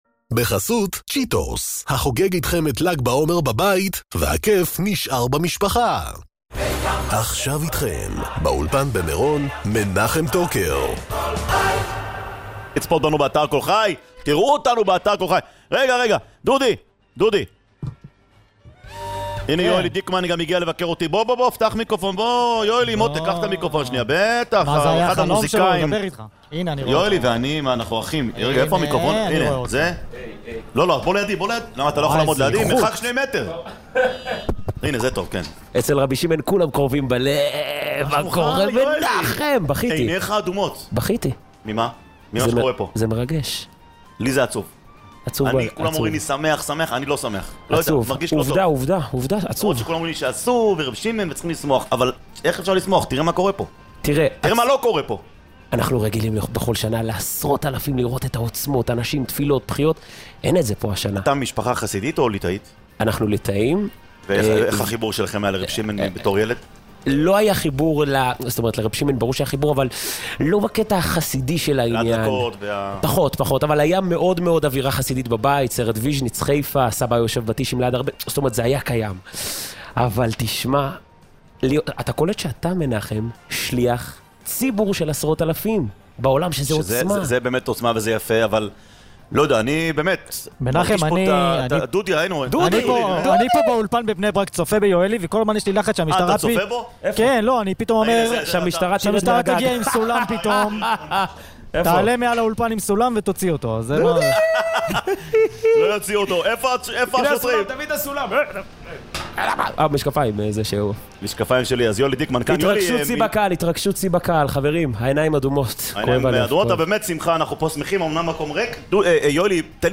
במסגרת האולפן השקוף במירון
אחדות בעם ישראל תיכף גם אתה מגיע לאולפן השקוף של קול ברמה האזינו לקטע המשעשע ממירון!!